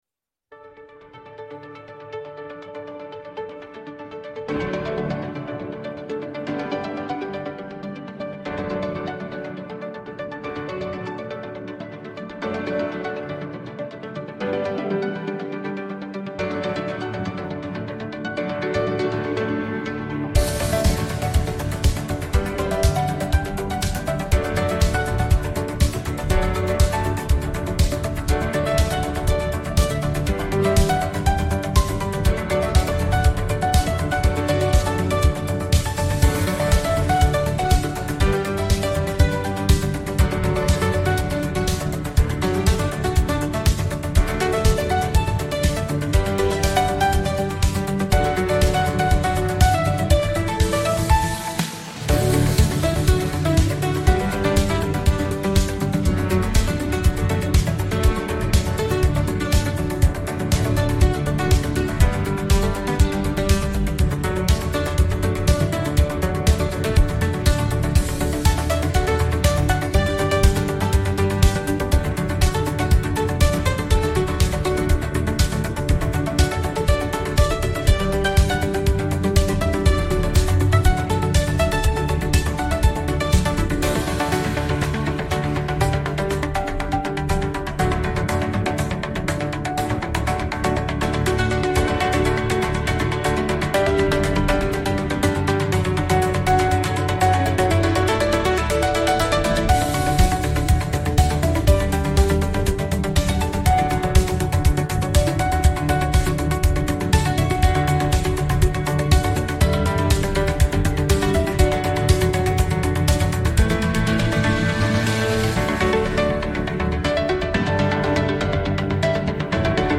צלילים רכים ונעימים המתמזגים עם שלוות הטבע בשעות בין ערביים. ניגון רגוע שמשרה תחושת שלווה, הרהור פנימי, והתחברות לרגע הנוכחי. מתאים למדיטציה, זמן מנוחה, או רקע רגוע לעבודה וכתיבה.